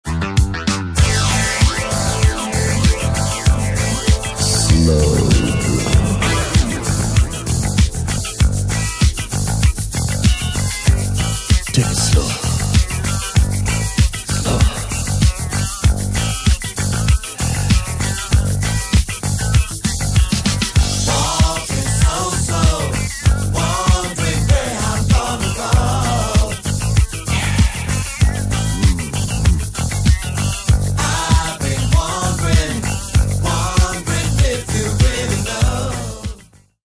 Genere:   Disco | Funky |